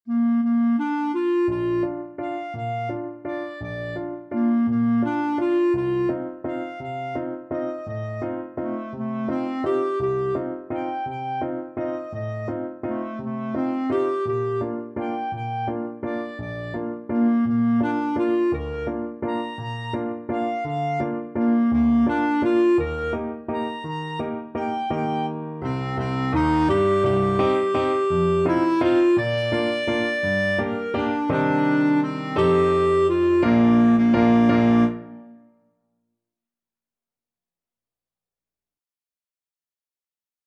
ClarinetPiano
=169 Steady one in a bar
3/4 (View more 3/4 Music)
Clarinet  (View more Easy Clarinet Music)
Classical (View more Classical Clarinet Music)